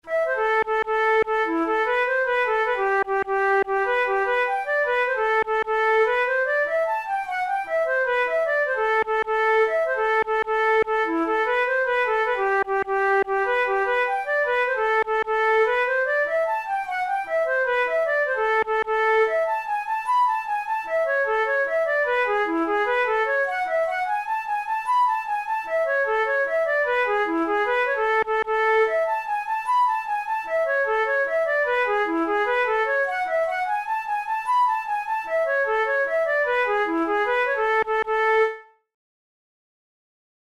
InstrumentationFlute solo
KeyA minor
Time signature6/8
Tempo100 BPM
Jigs, Traditional/Folk
Traditional Irish jig